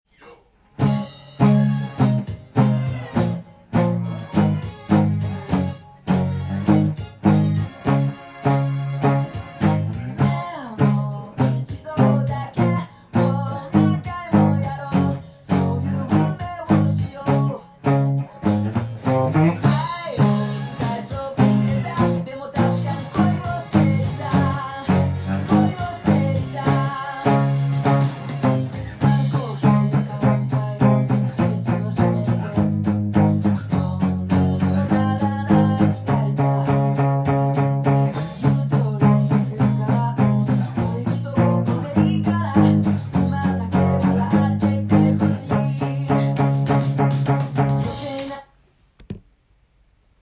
音源に合わせて譜面通り弾いてみました
なんと４分音符と８分音符しかでてきません。
keyがC♯なのでギタリストにが苦手なkeyになりますが
ほとんどパワーコードなので使用する指も２本で